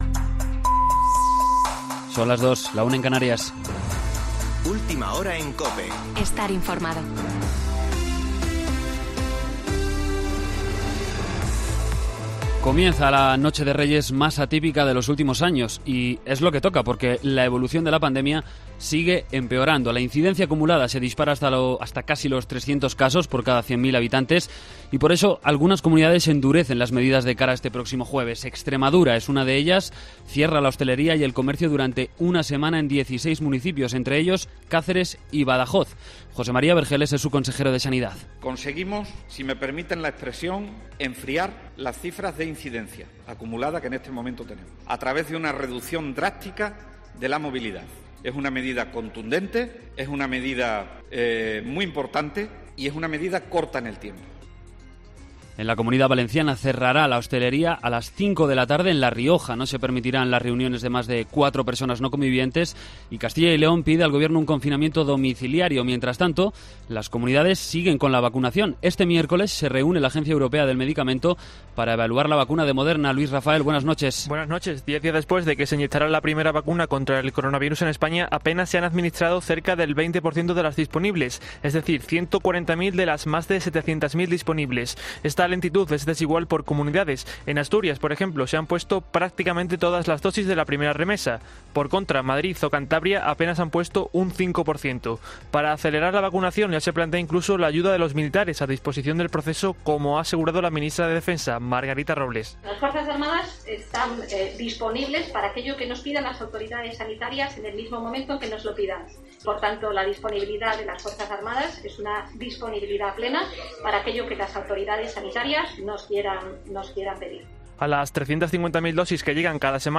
Boletín de noticias COPE del 6 de enero de 2020 a las 02.00 horas